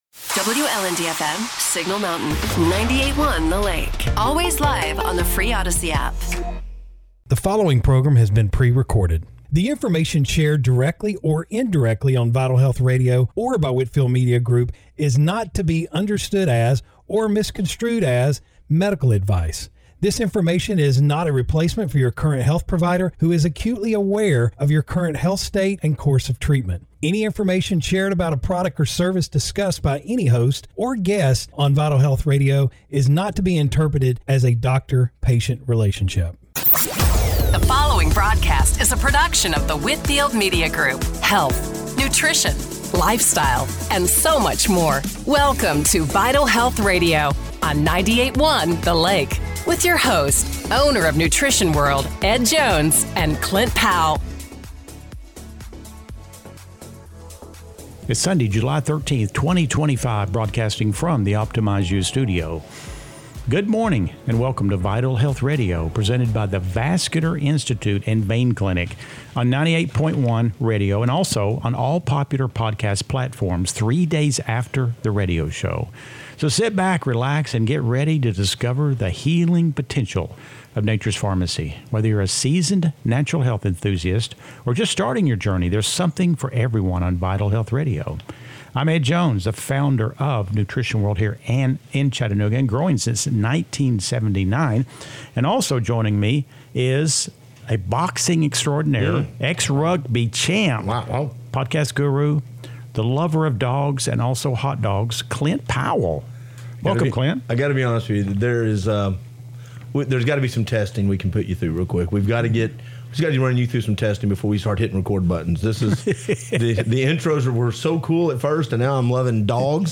Radio Show – July 13, 2025 - Vital Health Radio